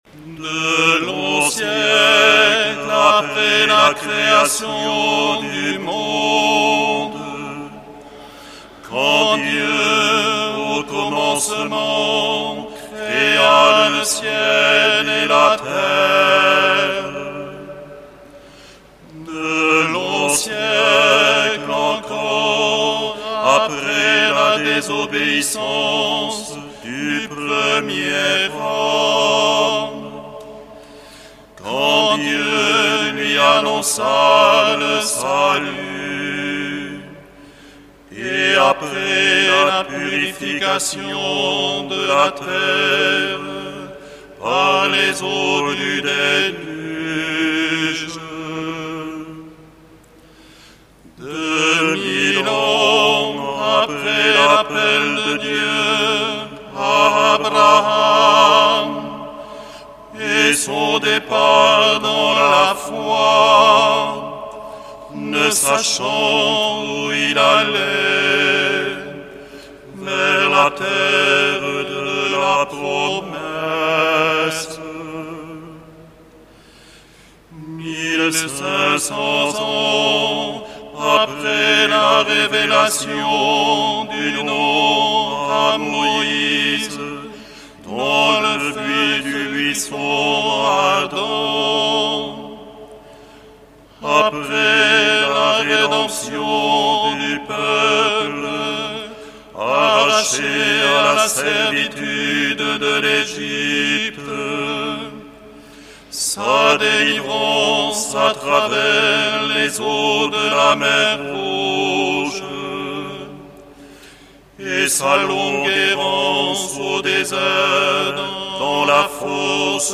25 décembre 2010 - Bayonne cathédrale - Hymnes et cantiques : extraits